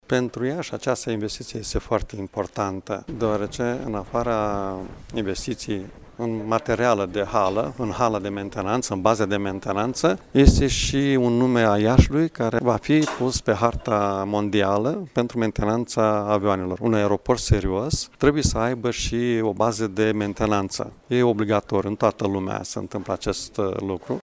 Contractul a fost semnat astăzi şi prevede concesionarea unei suprafeţe de teren de 16 mii de metri pătraţi pe o perioadă de 49 de ani – a precizat preşedintele Consiliului Judeţean Iaşi, Maricel Popa: